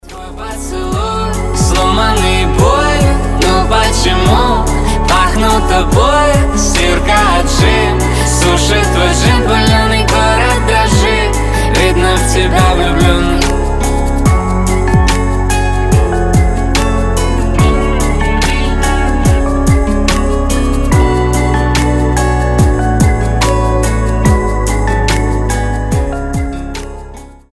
• Качество: 320, Stereo
лирика
грустные
дуэт